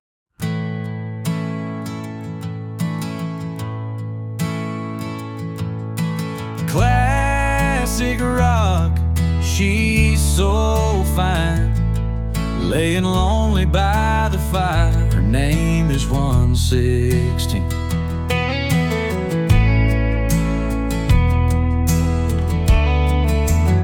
Classic Rock